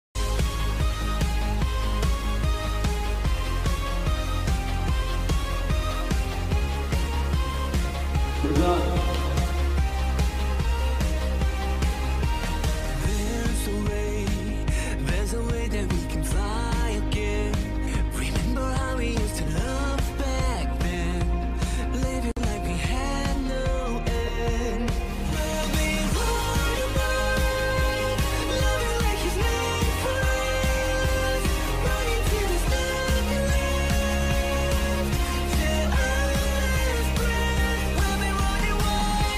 RUNSEOKJIN_EP.TOUR in JAPAN BEHIND Seokjin’s rehearsal